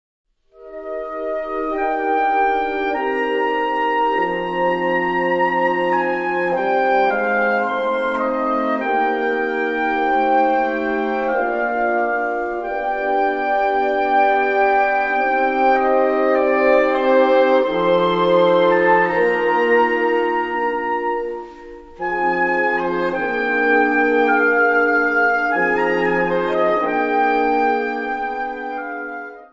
Gattung: Konzertmusik
Besetzung: Blasorchester